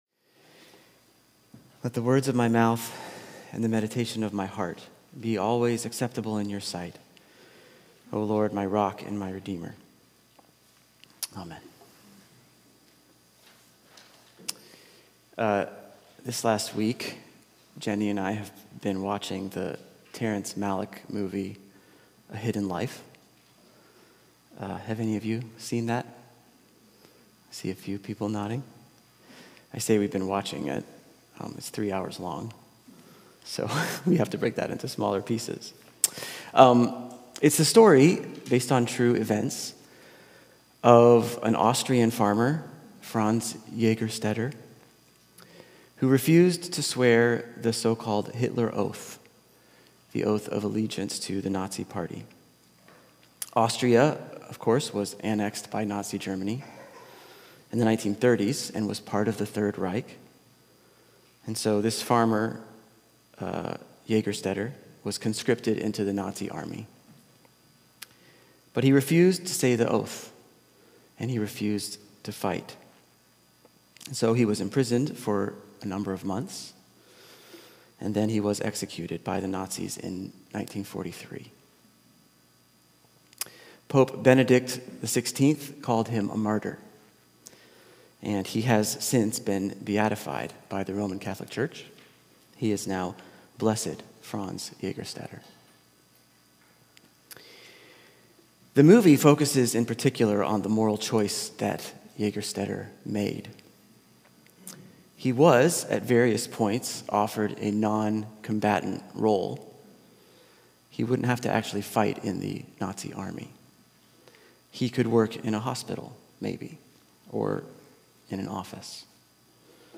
This podcast features recordings from Church of the Redeemer in Highwood, Illinois.